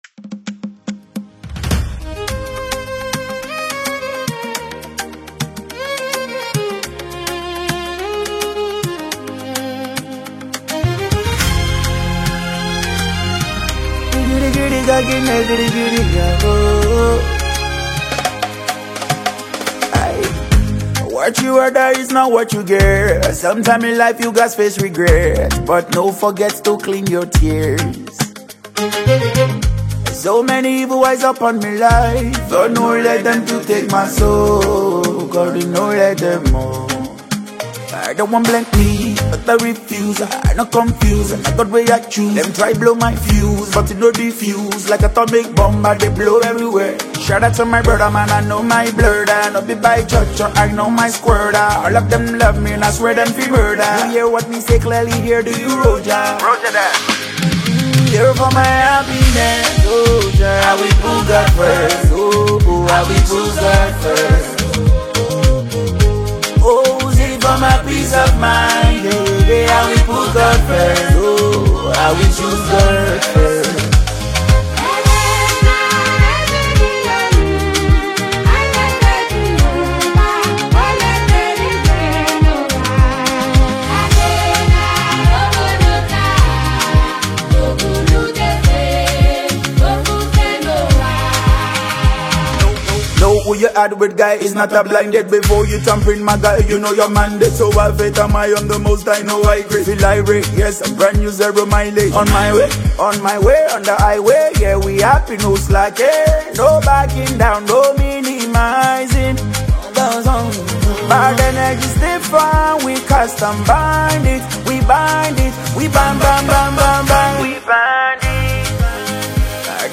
Nigerian singer and songwriter